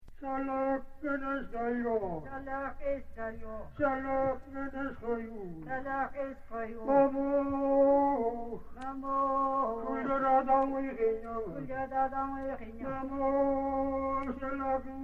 Очень хороший отрывок шаманской музыки mp3